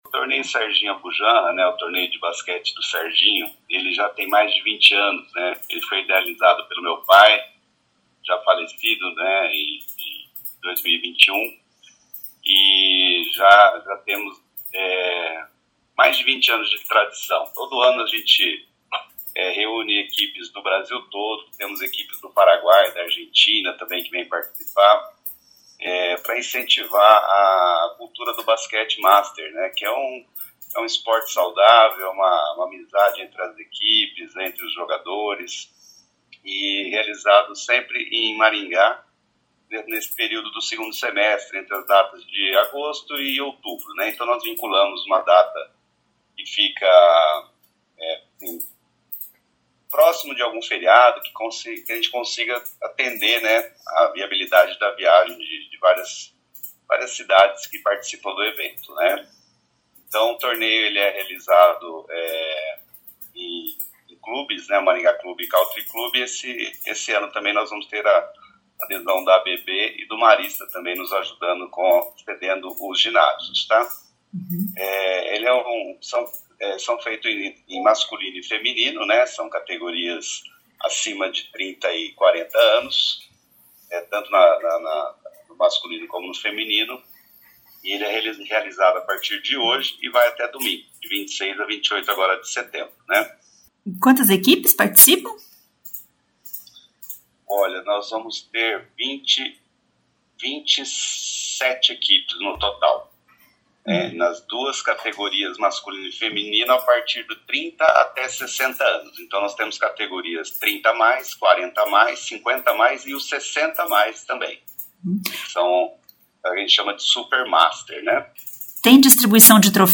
Um dos organizadores